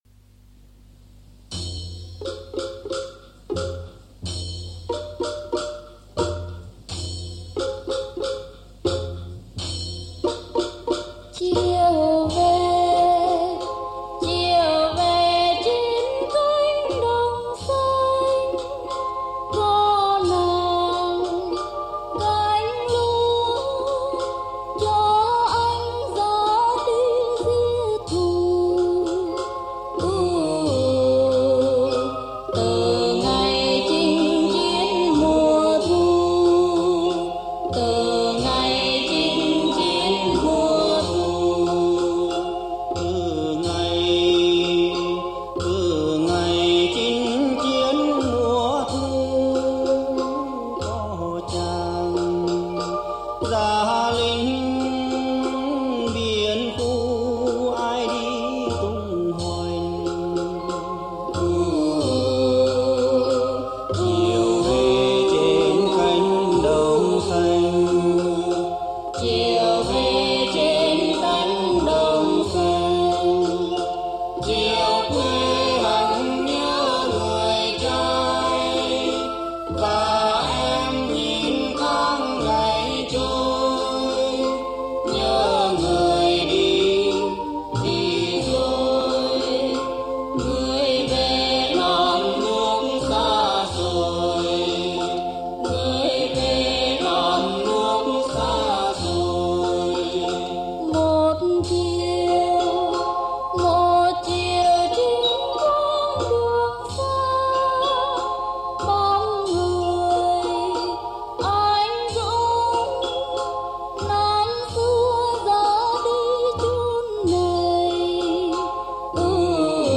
Dân Ca Mới